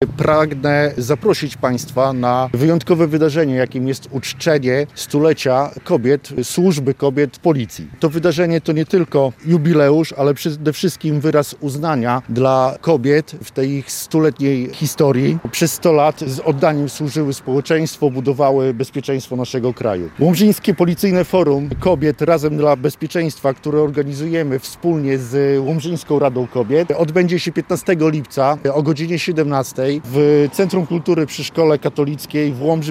Do udziału zachęca Komendant Miejskiej Policji w Łomży, inspektor Adam Chodziutko.